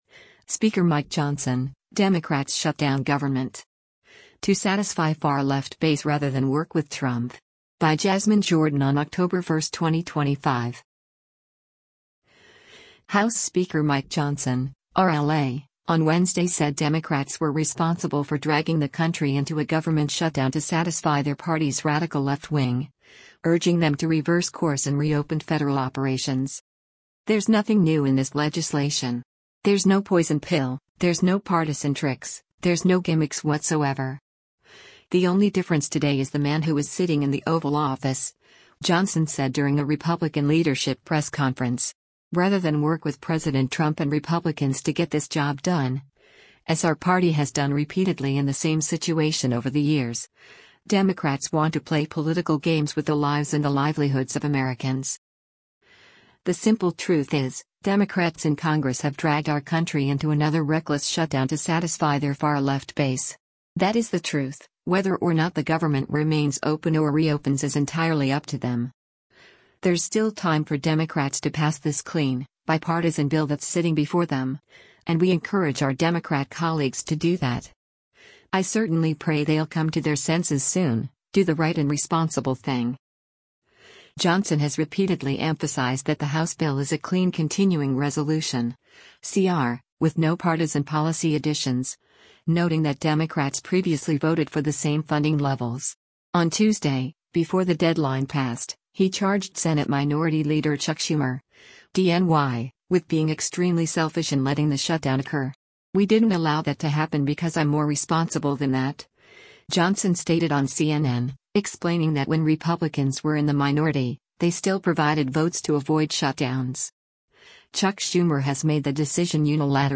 “There’s nothing new in this legislation. There’s no poison pill, there’s no partisan tricks, there’s no gimmicks whatsoever. The only difference today is the man who is sitting in the Oval Office,” Johnson said during a Republican leadership press conference.